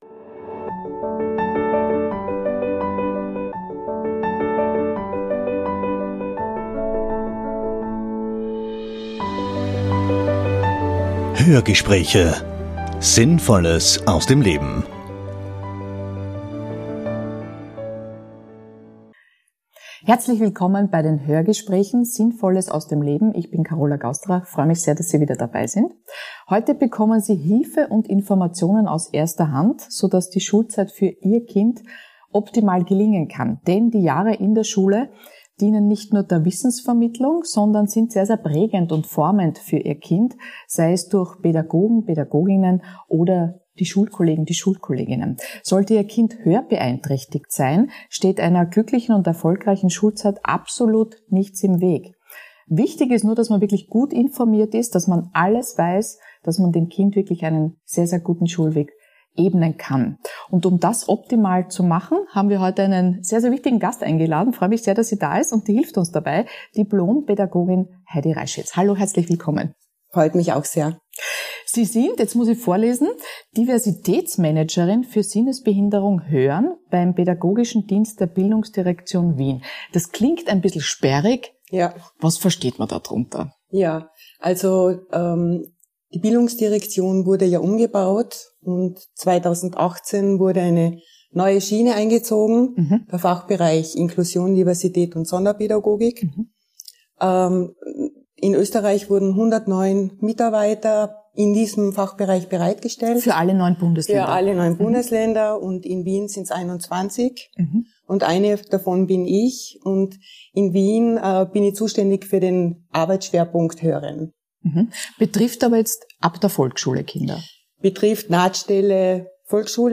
Das Cochlea-Implantat ist eine Errungenschaft, berichtet die langjährige Lehrerin und ermöglicht vielen Kindern den Besuch der Regelschule. Oberstes Ziel ist es, den bestmöglichen Schulplatz zu finden und einen guten Zugang zur Bildung zu ermöglichen. Informatives zum Nachteilsausgleich bei Leistungsbeurteilungen, Unterstützungsmöglichkeiten für Eltern und Lehrer sowie Einblicke über ihre persönlichen Erfahrungen mit Hörverlust gibt die erfahrene Pädagogin im Hörgespräch.